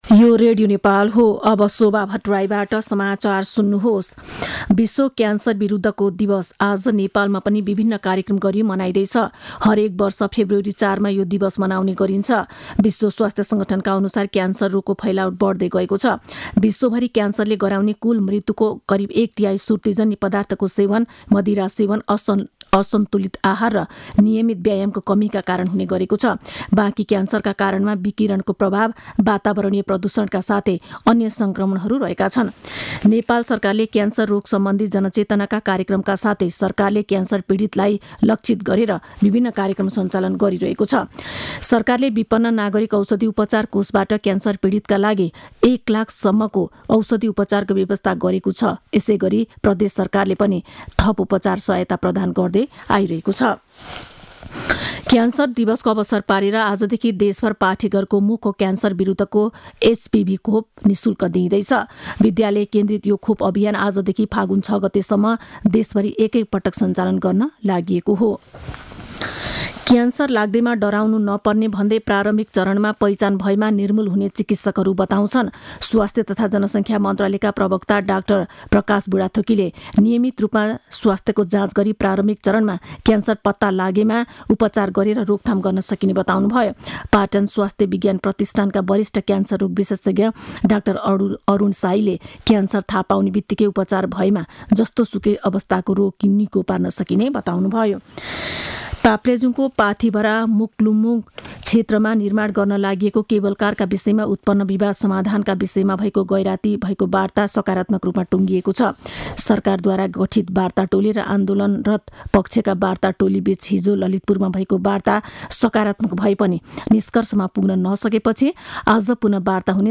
मध्यान्ह १२ बजेको नेपाली समाचार : २३ माघ , २०८१
12-am-news.mp3